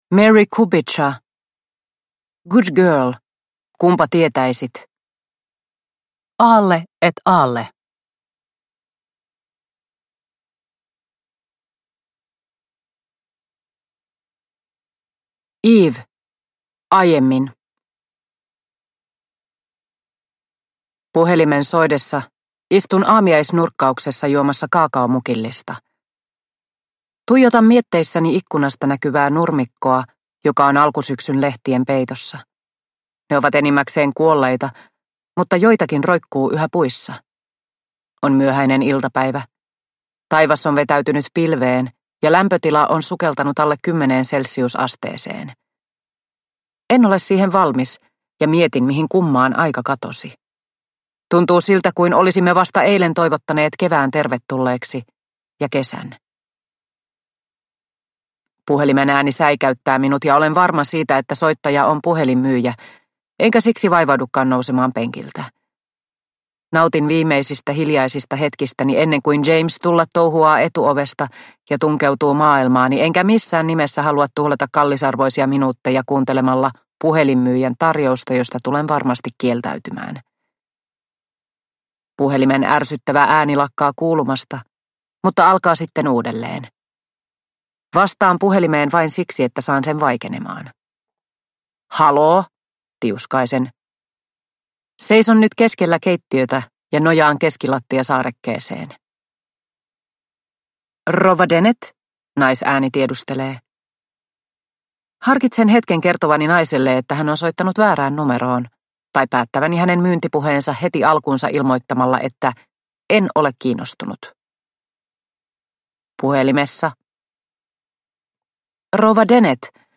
Good girl : kunpa tietäisit – Ljudbok – Laddas ner